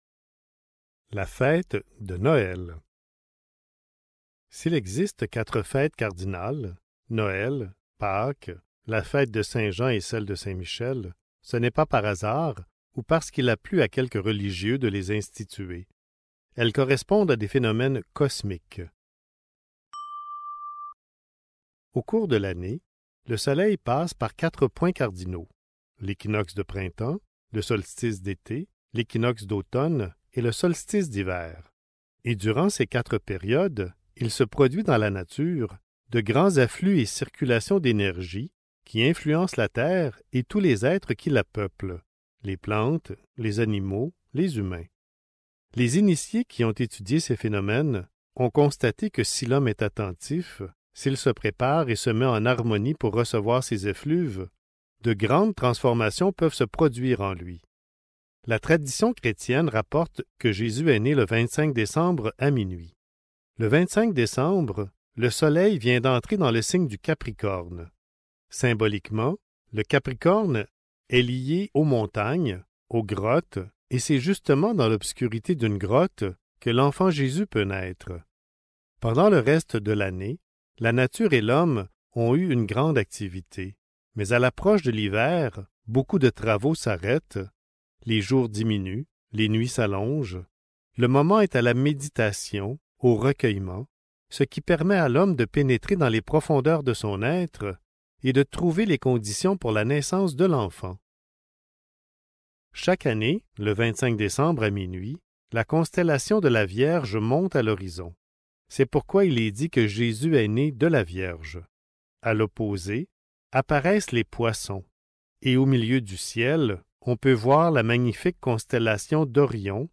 Des livres lus, des livres à écouter !